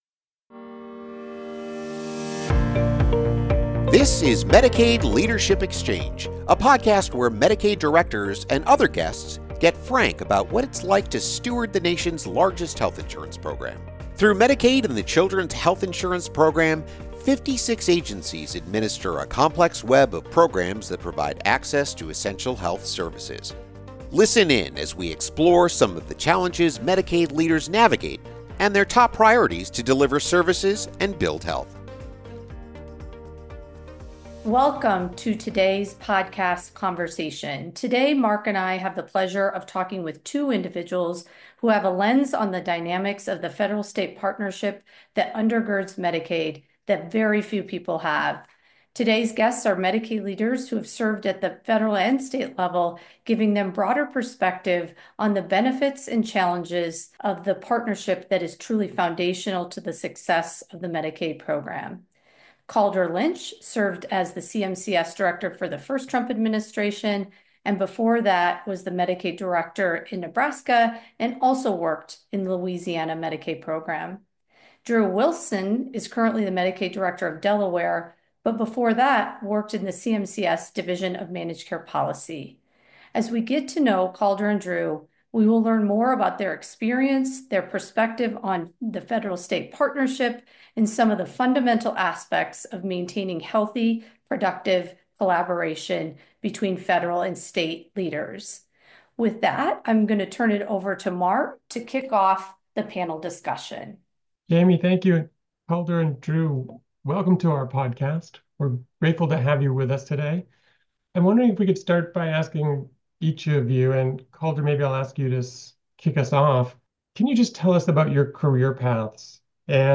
The episode features perspectives from two Medicaid leaders who have worked in both state and federal agencies